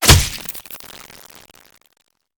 flesh1.ogg